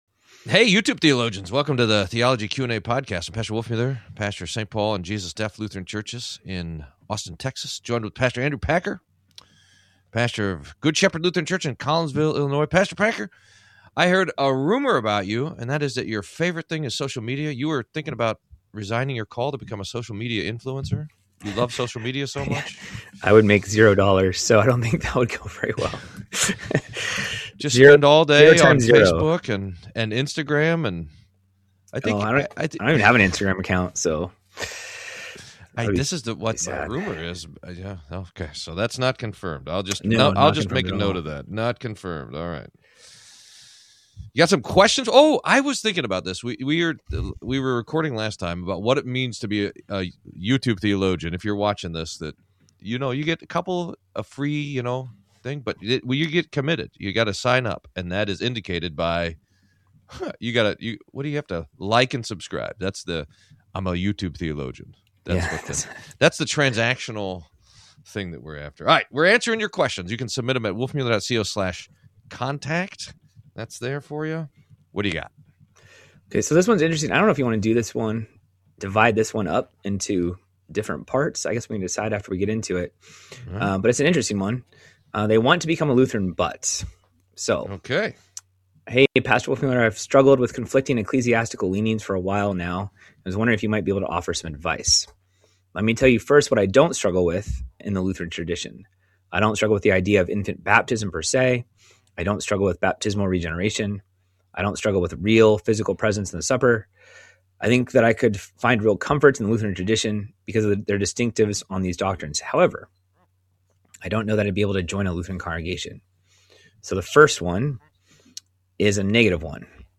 Q&A: Are Infants Guilty of Original Sin? What is Open Theism? More.